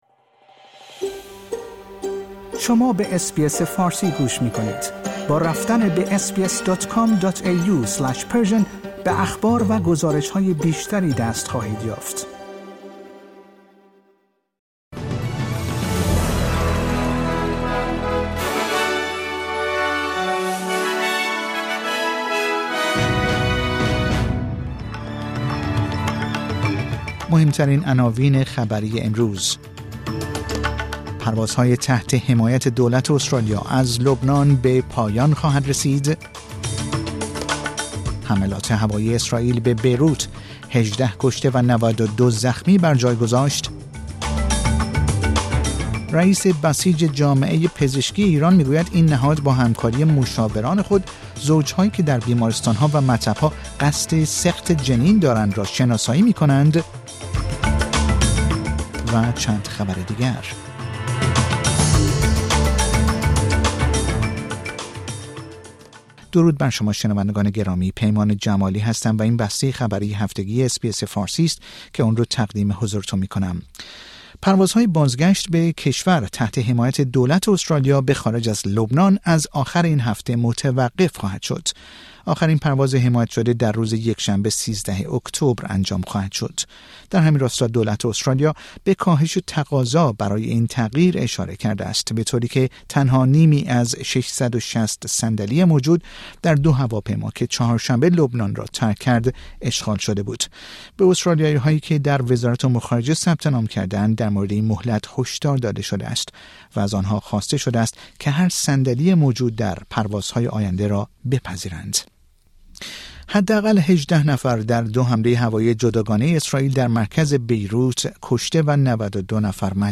در این پادکست خبری مهمترین اخبار استرالیا، جهان و ایران در یک هفته منتهی به شنبه ۱۲ اکتبر ۲۰۲۴ ارائه شده است.